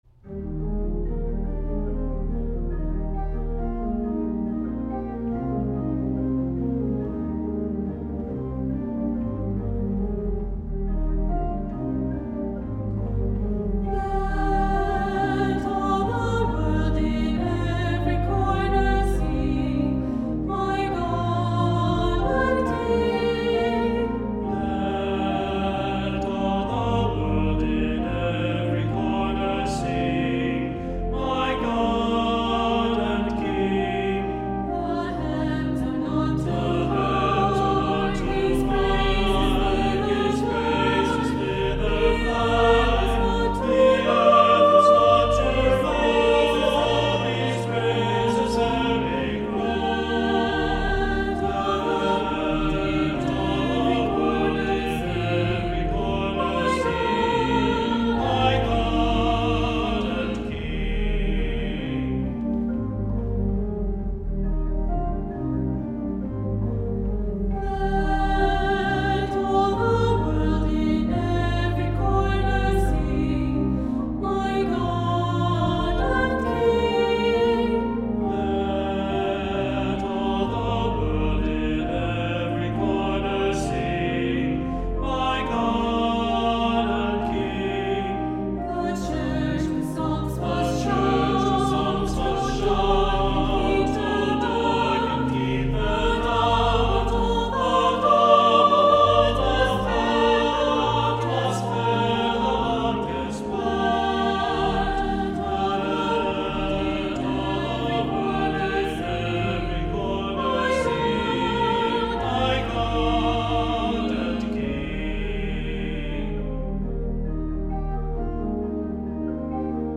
Voicing: Unison; Two-part equal; Two-part mixed